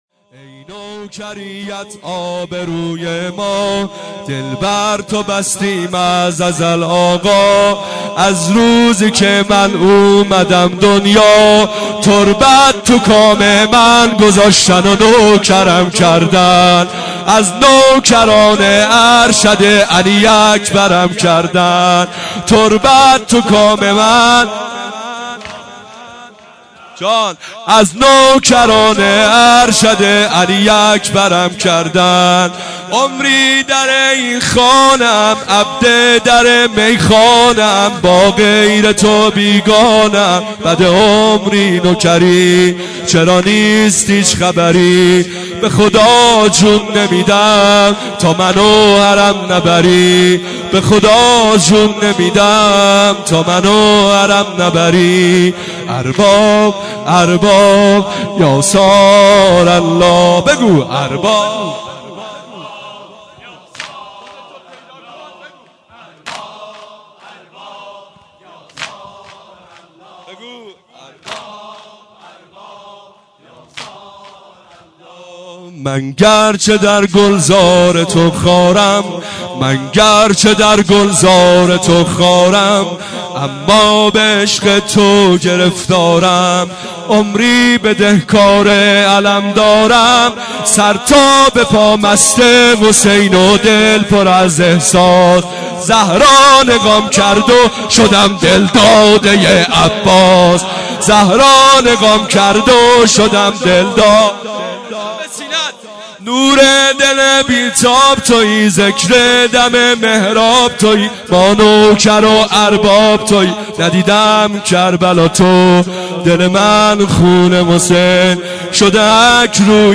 واحد حماسی | ای نوکریت
مداحی جدید